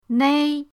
nei1.mp3